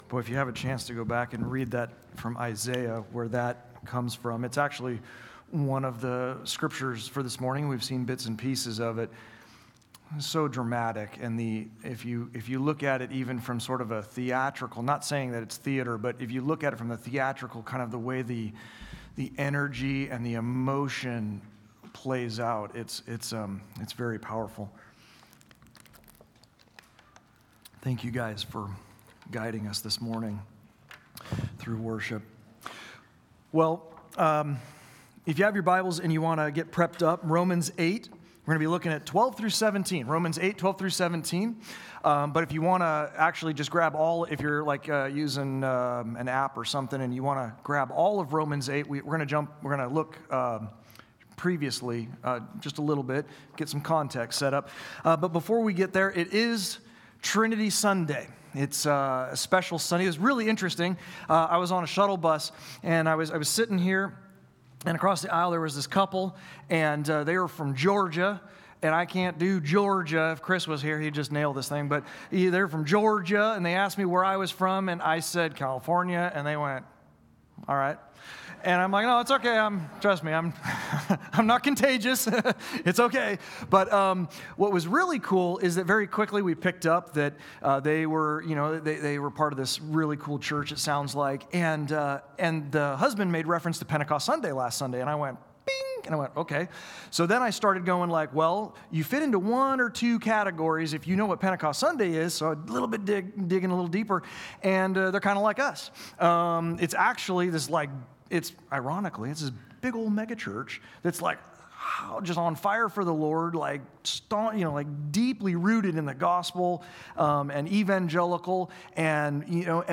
On this Sunday we explored Romans 8: 12-17. We discuss how IN Christ, THROUGH the power of the Holy Spirit and as HEIRS of God the Father, we are no longer obligated to the flesh.